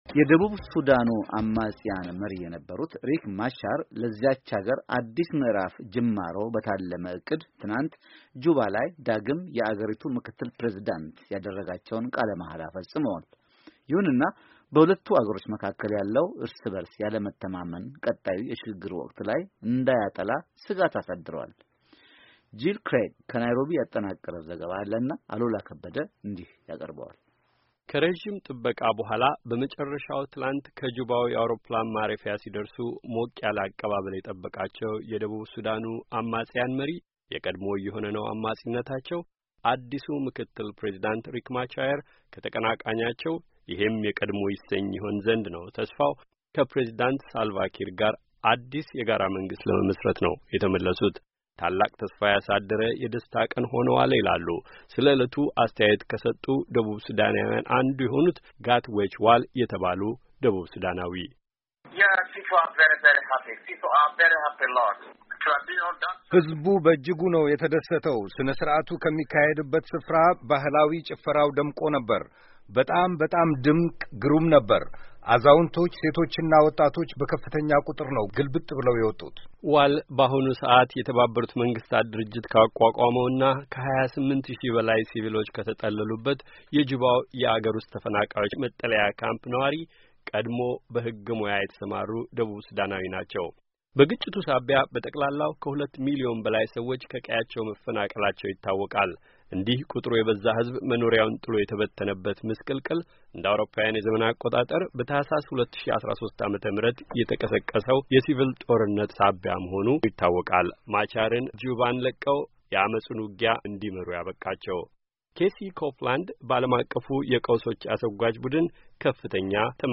ከናይሮቢ ያጠናቀረችው ዘገባ ዝርዝሩን ይዟል።